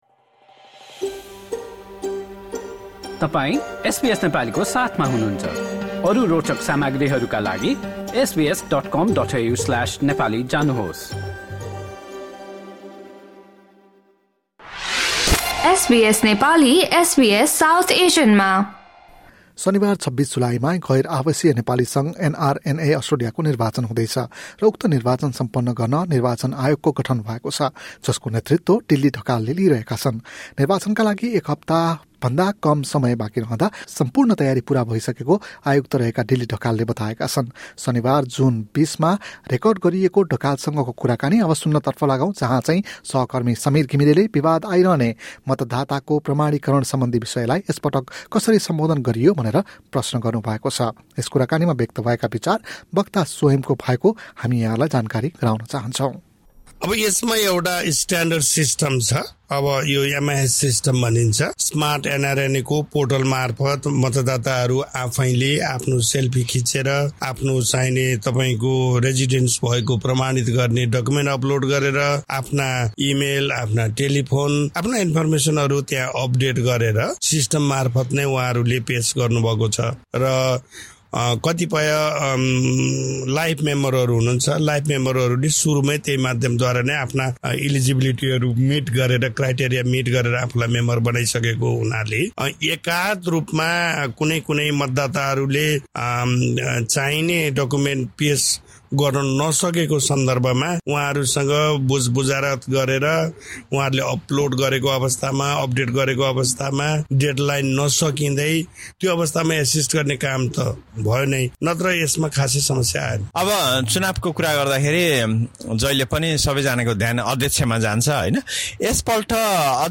एसबीएस नेपालीले गरेको कुराकानी।